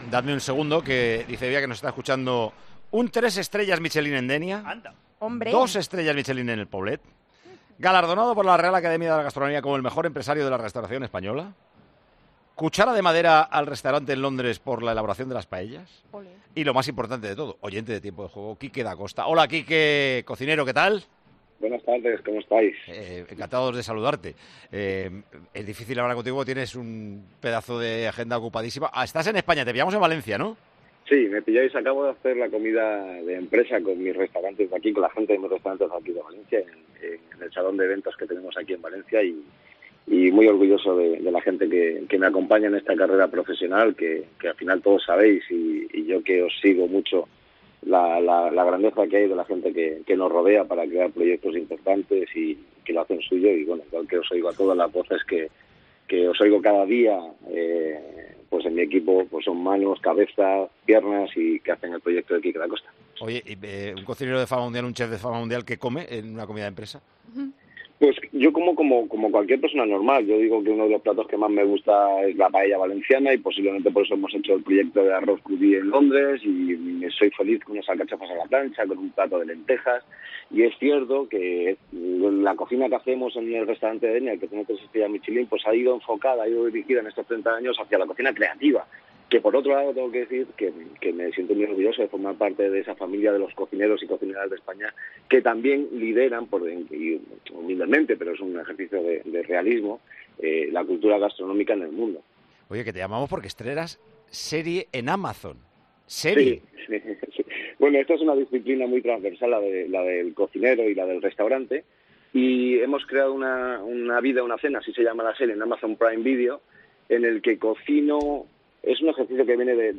El famoso chef atendió a Tiempo de Juego dos días antes del estreno de su serie en Amazon: 'Una cena, una vida'.